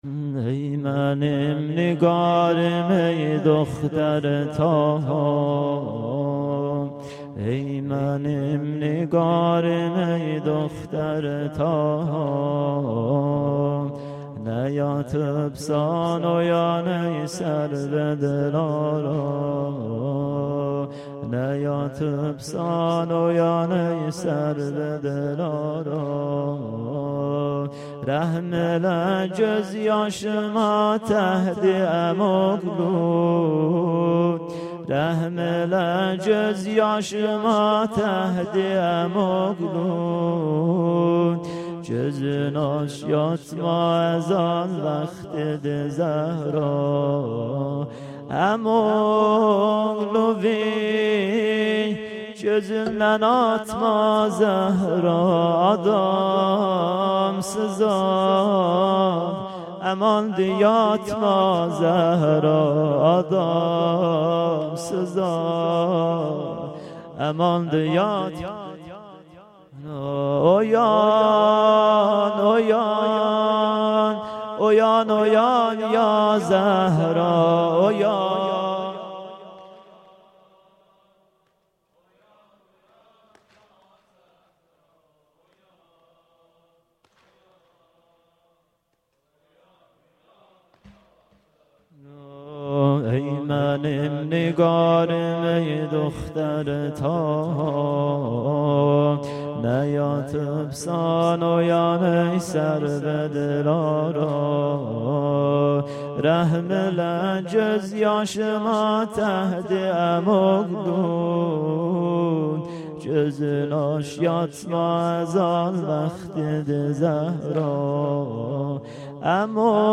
بخش دوم سینه زنی
فاطمیه 97